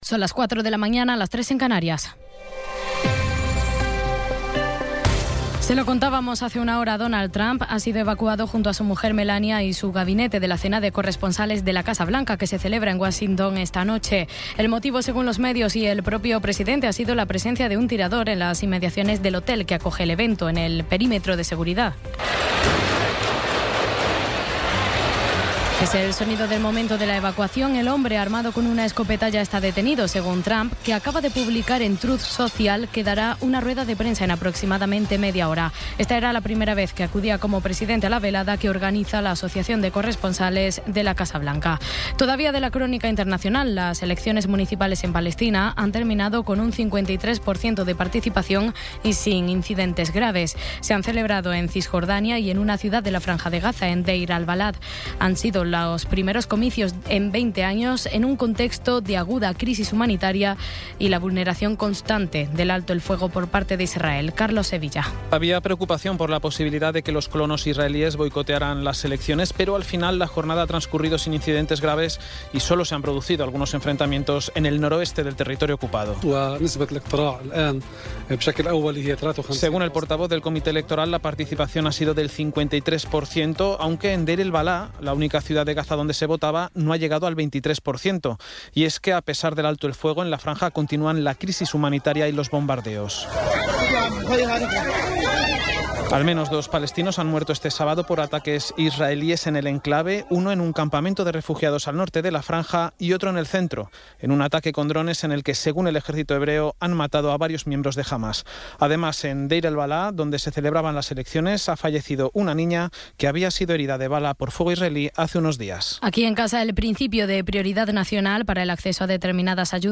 Resumen informativo con las noticias más destacadas del 26 de abril de 2026 a las cuatro de la mañana.